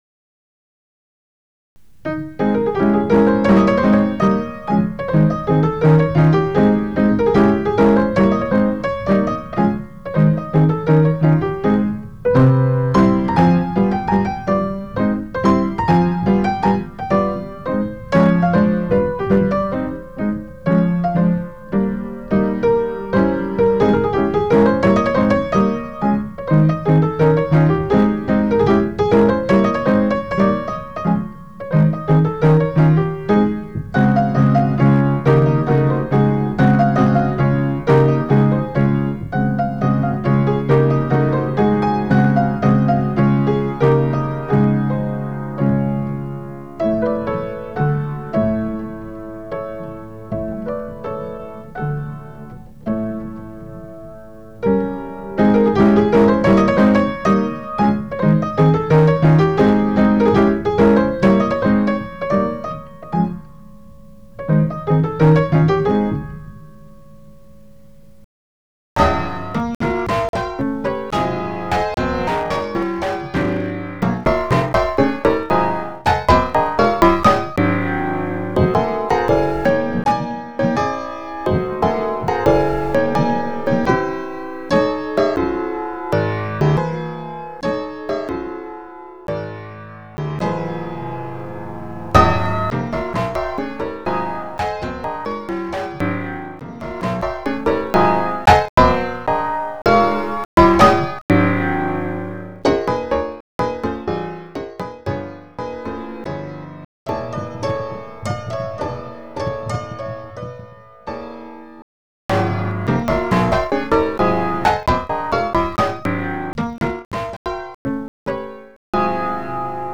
11 Variazioni su una Mazurka di Chopin; tema e variazione n. 3" per pianoforte solo.